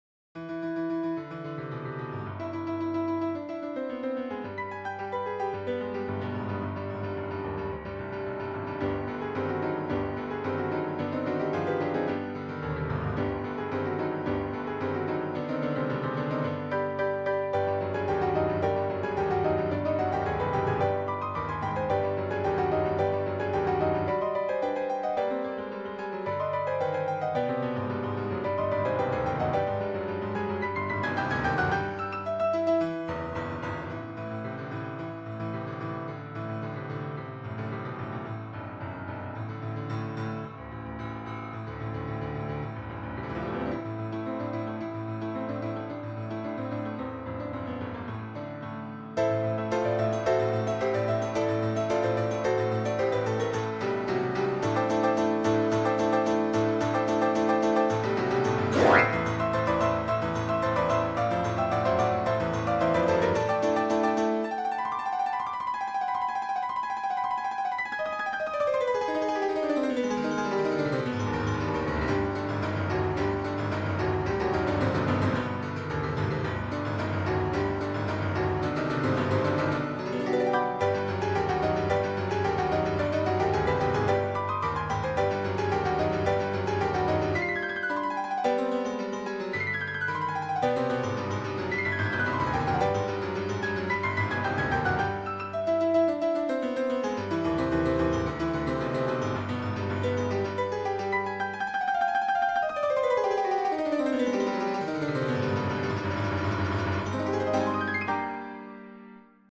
təsirli bir marşdır